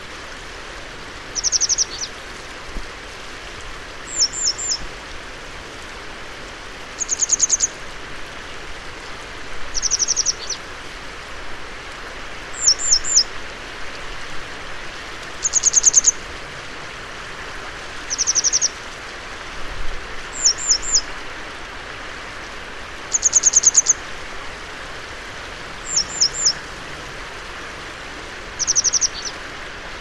Photos de Bergeronnette des ruisseaux - Mes Zoazos
Bergeronnette-ruisseaux.mp3